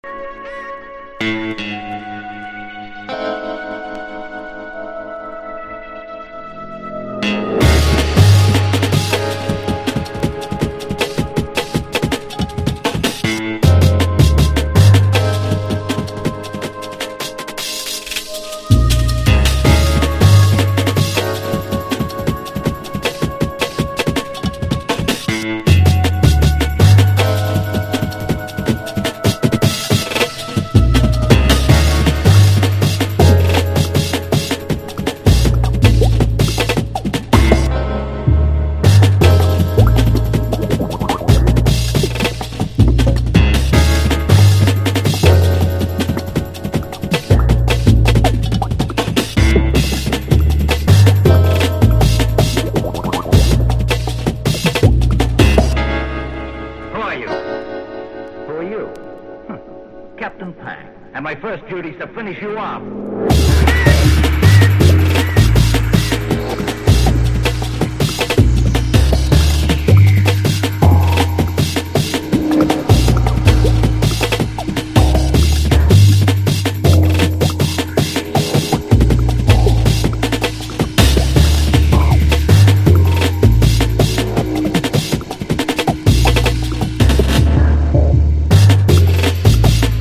高速なブレイク～ドラムンな展開にオリエンタルなメロディがはまったトラック！